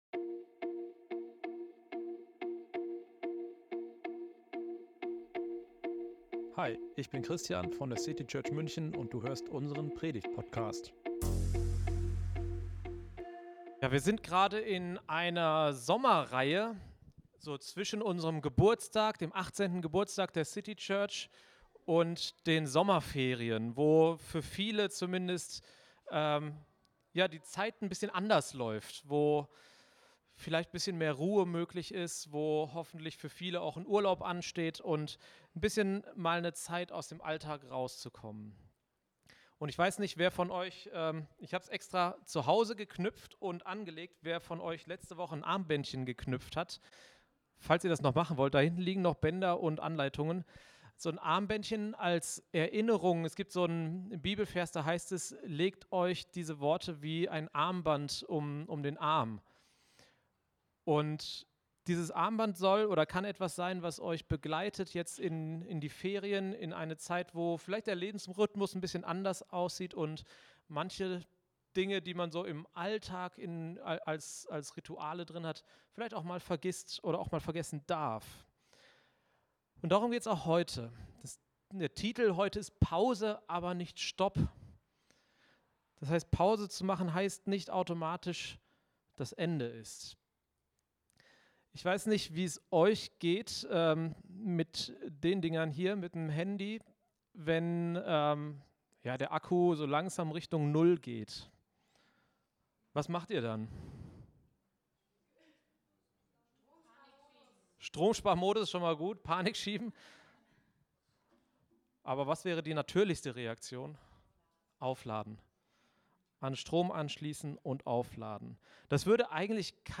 Pause, aber nicht Stopp – ein Gottesdienst über die Kunst, zur Ruhe zu kommen, ohne den Kontakt zu Gott zu verlieren.